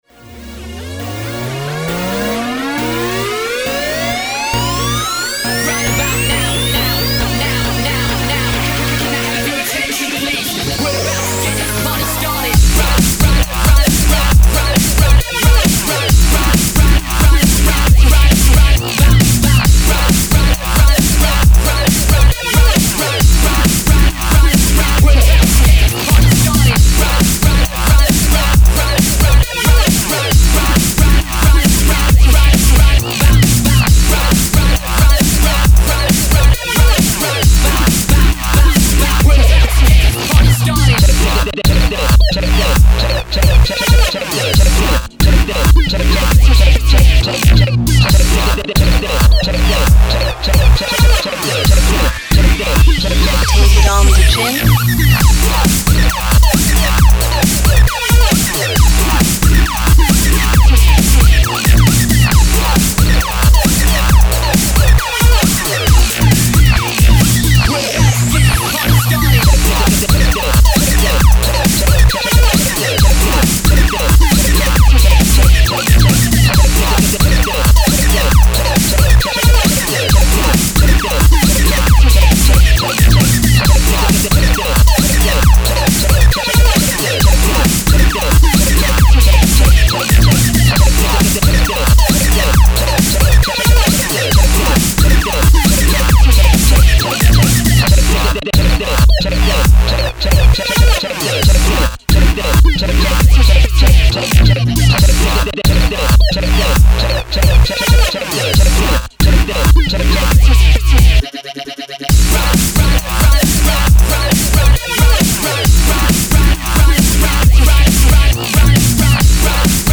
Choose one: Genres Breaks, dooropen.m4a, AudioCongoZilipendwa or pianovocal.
Genres Breaks